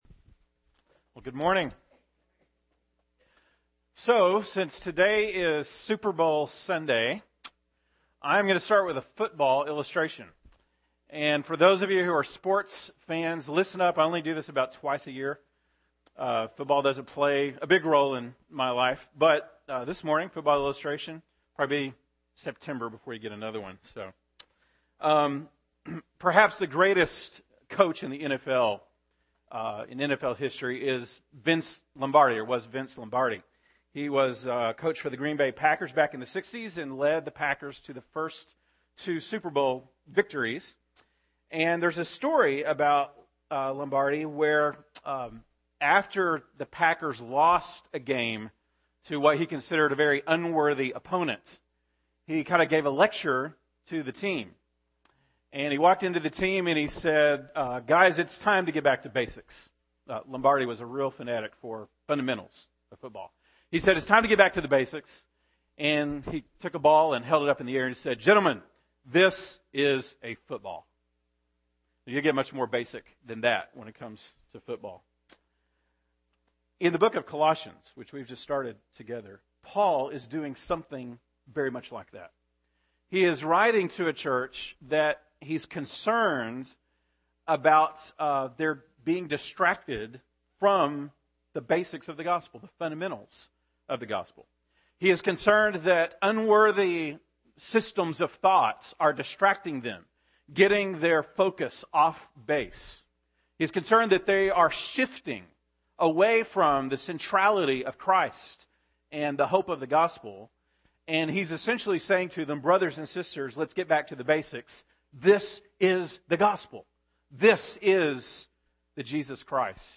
February 3, 2013 (Sunday Morning)